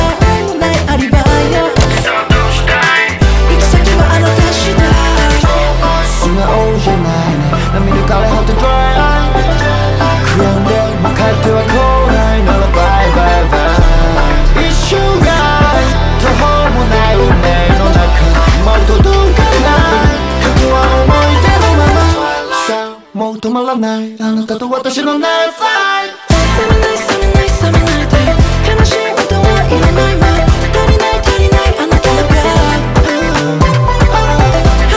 2025-04-18 Жанр: Альтернатива Длительность